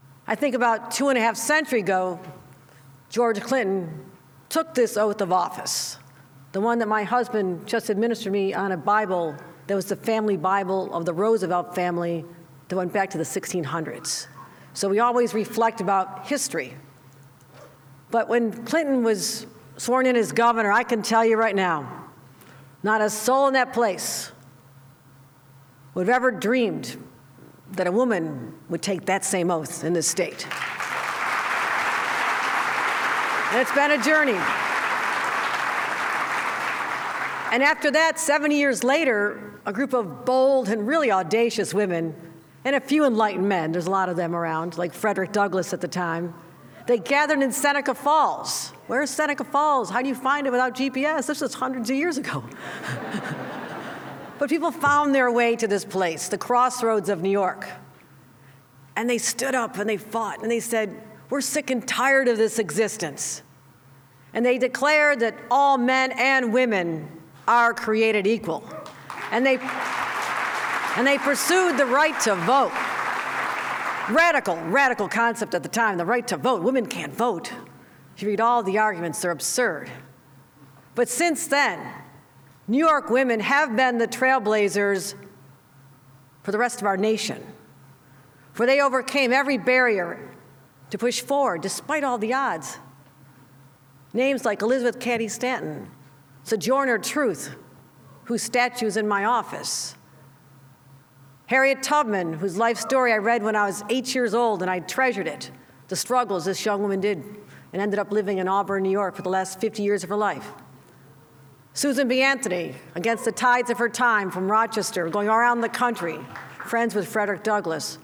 Two Finger Lakes communites got a shoutout from the governor during her inaugurations speech.